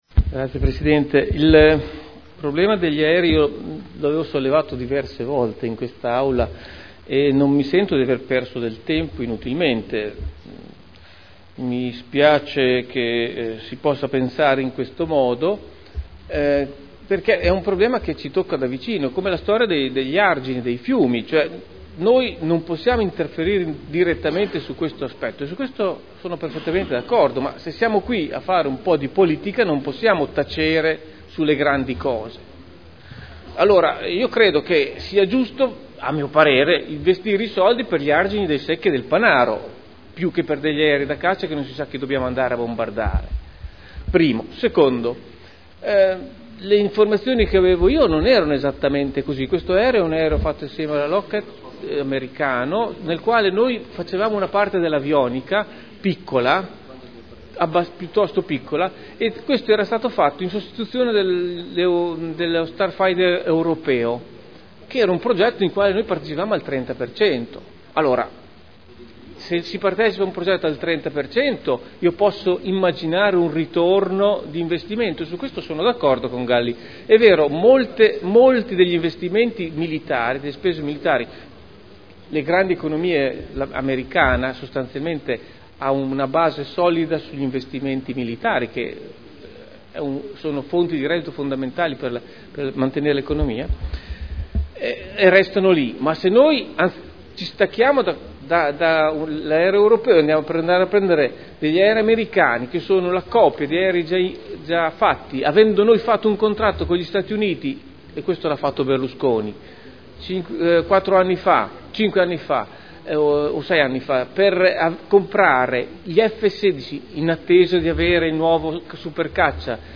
Luigi Alberto Pini — Sito Audio Consiglio Comunale
Dibattito